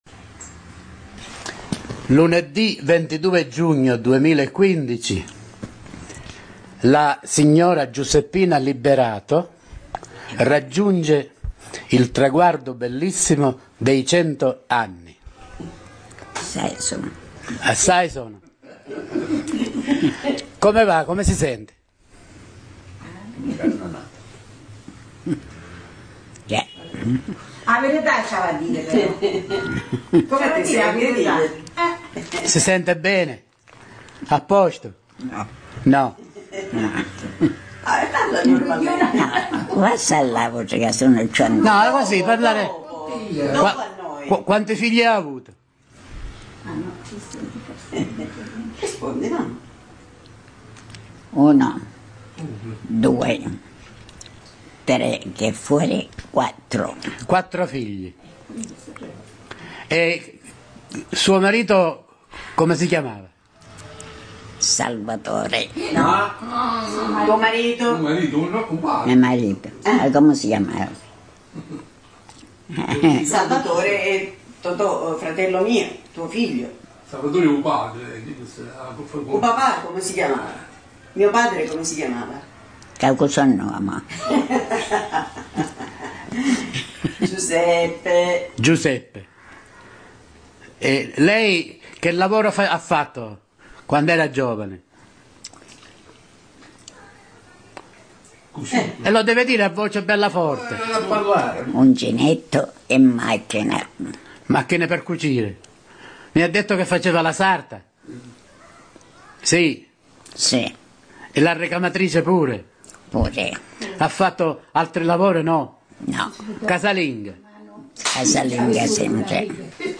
Audio Intervista/mp3